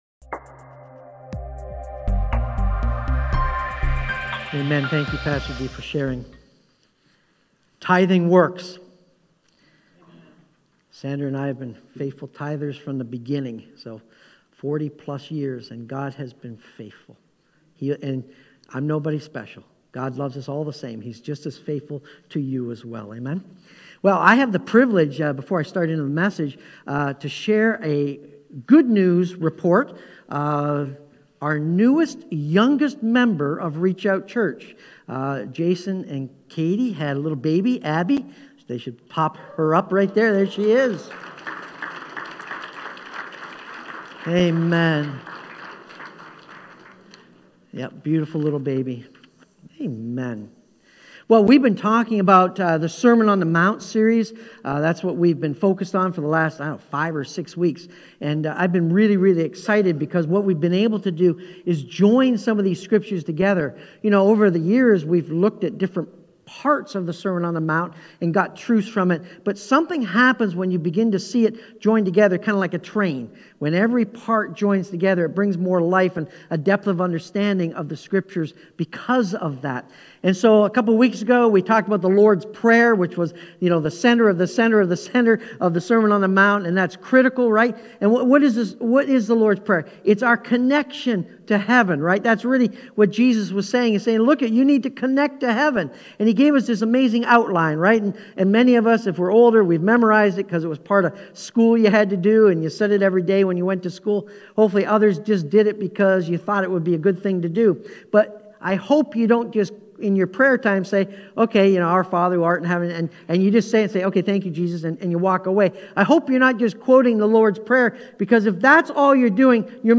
Sermon-On-The-Mount-Its-All-About-How-We-Act.mp3